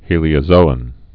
(hēlē-ə-zōən)